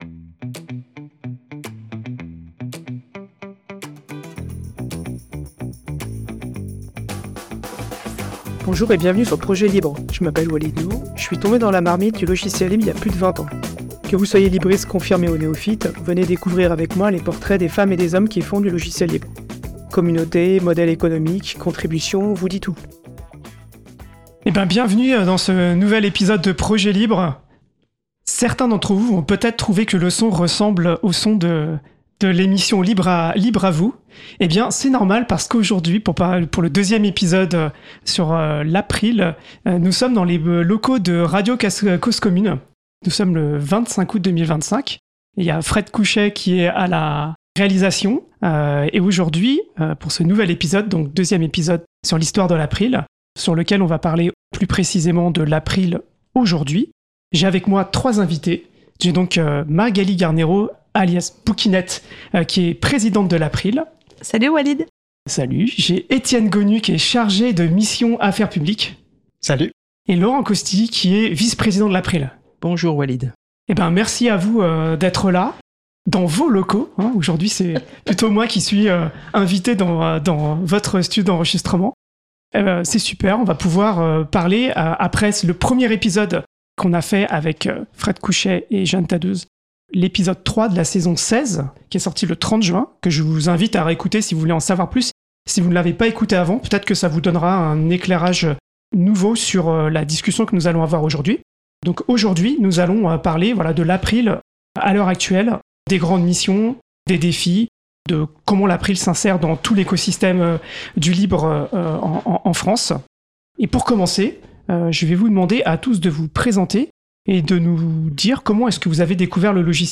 Podcast Projets Libres ! - Enregistré dans le studio de radio Cause Commune